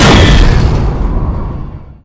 bladeslice1.ogg